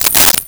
Coins Shake In Hand 02
Coins Shake in Hand 02.wav